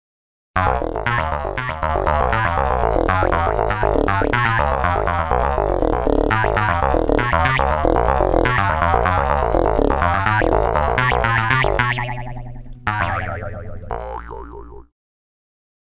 Two examples of a single Jaw Harp sample played quickly with a random 12 steps pitch variation:
JawHarp_1note_rndpitch_05.mp3